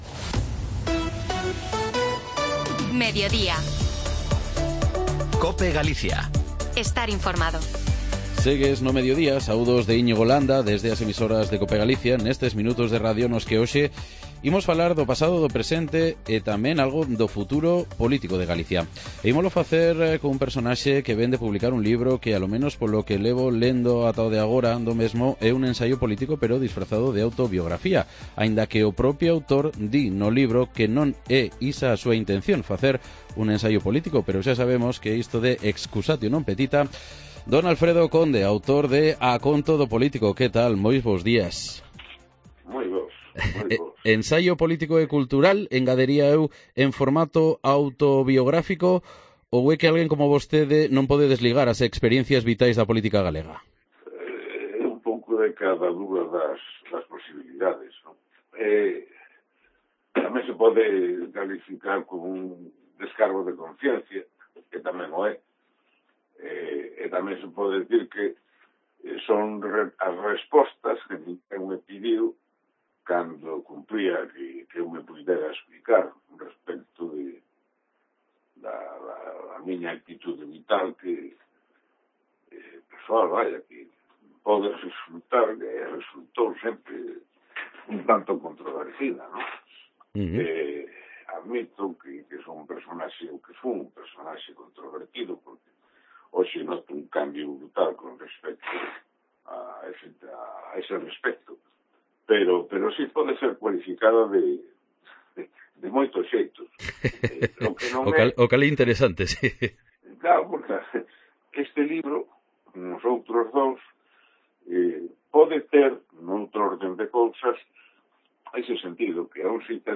AUDIO: Hablamos con el escritor Alfredo Conde que acaba de publicar su último trabajo, A Conto do Político en el que, a modo de ensayo...